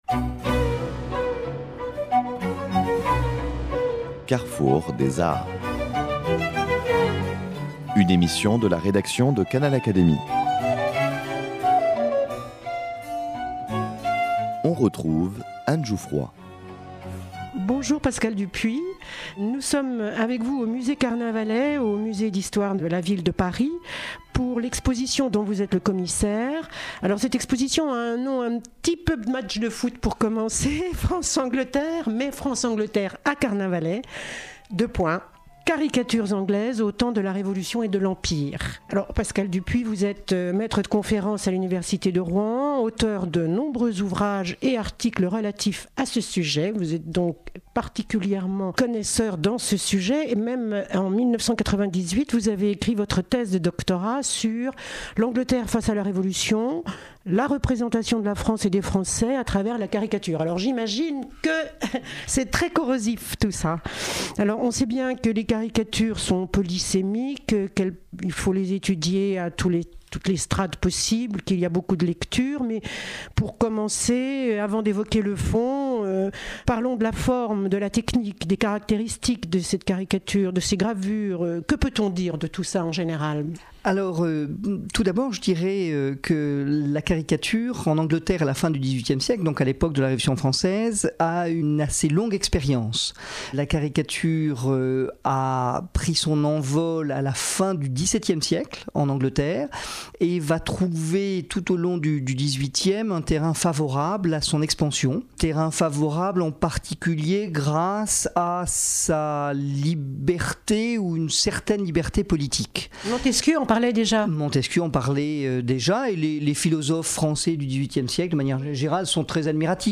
Au musée Carnavalet, musée de l’Histoire de la Ville de Paris